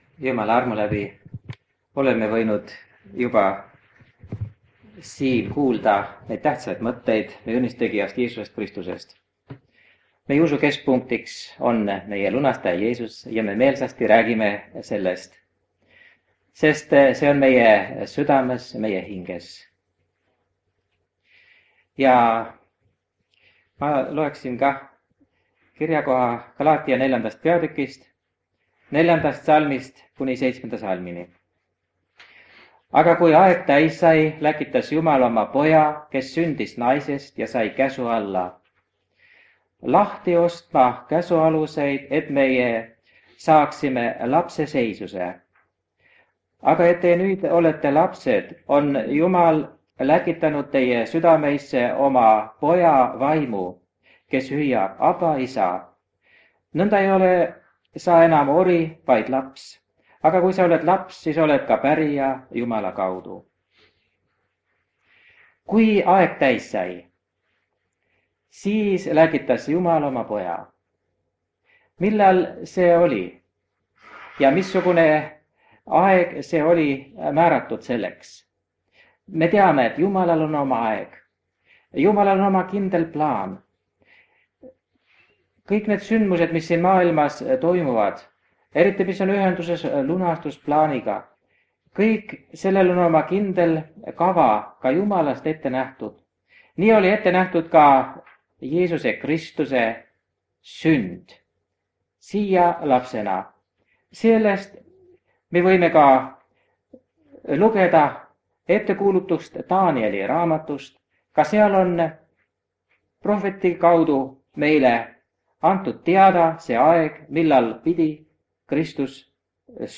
Jutlus vanalt lintmaki lindilt 1976 aastast.